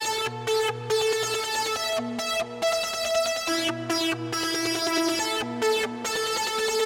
CCD Lo Fi Glitter Synth A Minor 140
描述：这是一种使用lo fi技术创作的奇怪的旋律。可以用于杜比斯特普以外的东西，如Breakbeat或glitch或类似的东西。这是在A小调。
Tag: 140 bpm Dubstep Loops Synth Loops 1.15 MB wav Key : A